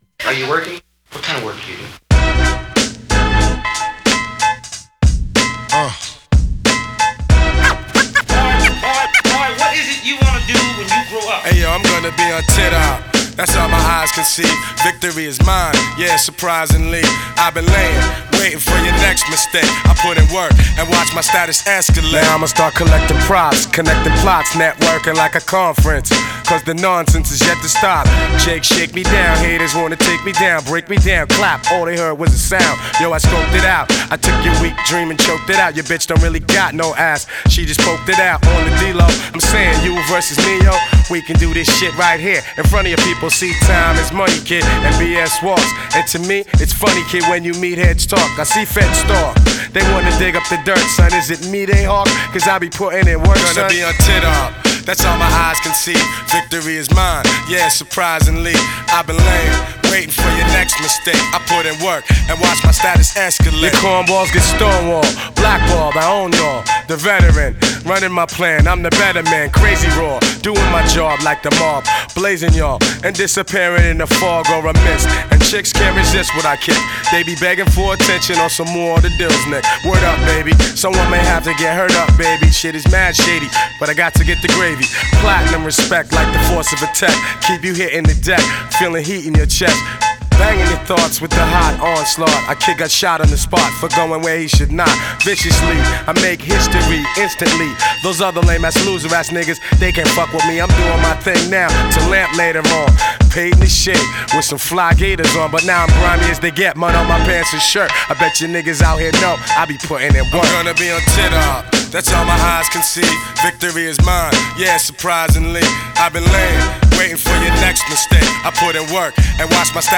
hip hop rap old school